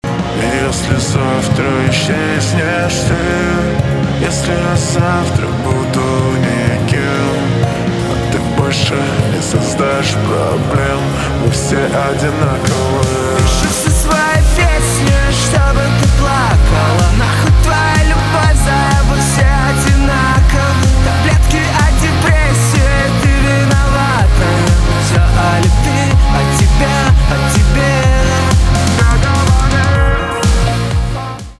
альтернатива
грустные , печальные , гитара , барабаны
жесткие